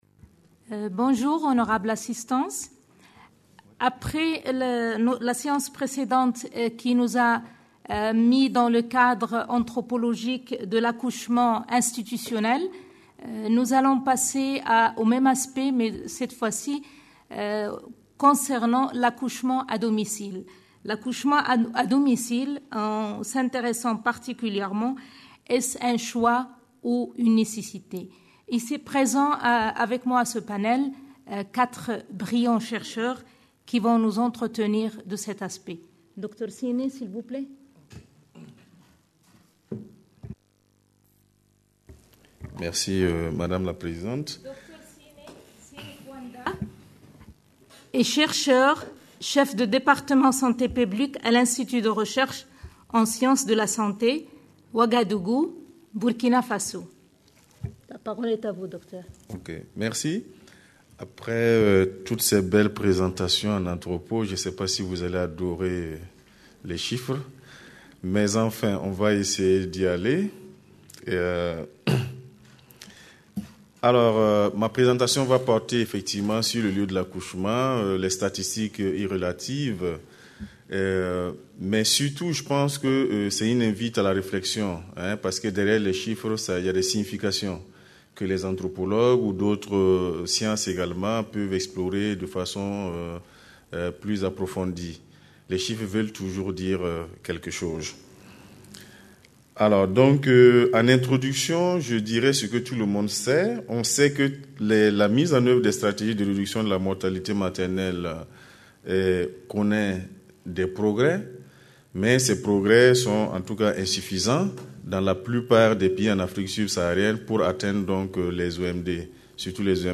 Conférence enregistrée dans le cadre du Colloque International Interdisciplinaire : Droit et Santé en Afrique. Réduction de la mortalité maternelle en Afrique Sub-saharienne, mieux comprendre pour mieux agir. 4ème session : accouchement à domicile, choix ou nécessité.